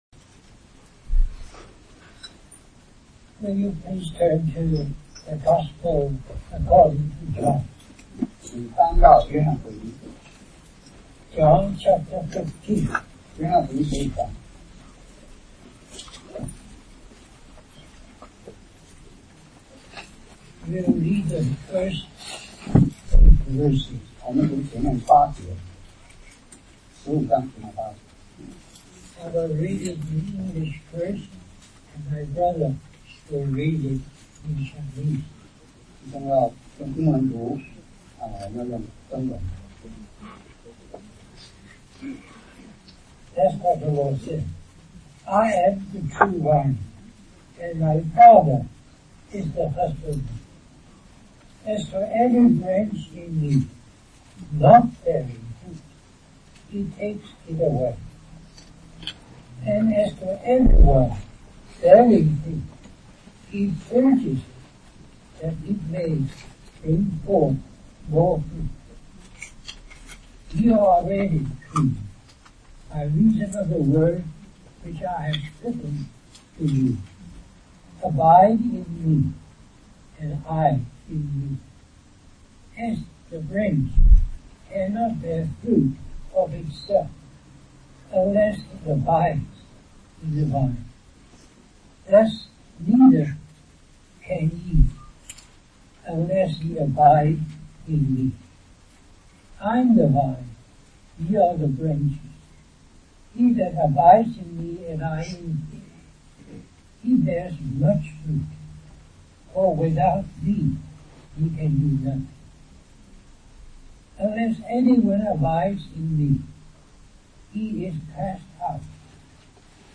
We apologize for the poor quality audio
Our brother shares on our call to abide in the Lord & bearing fruit (audio quality is poor)